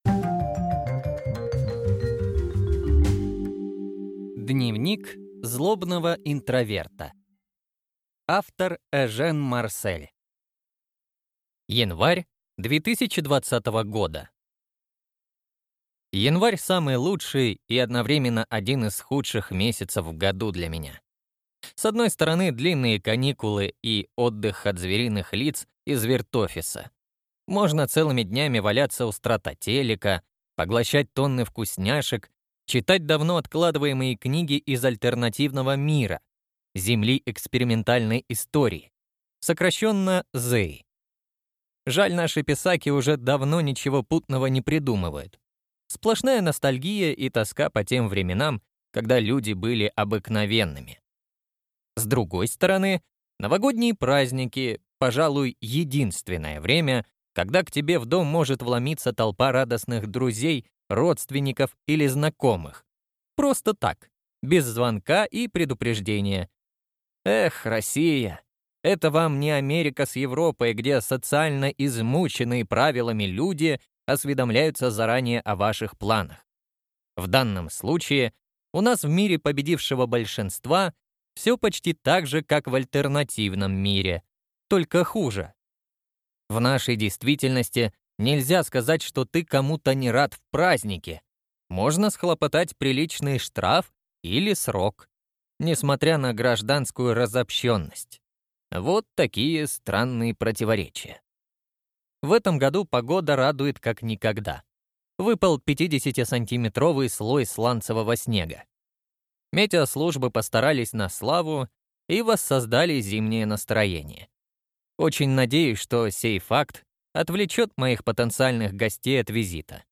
Аудиокнига Дневник злобного интроверта | Библиотека аудиокниг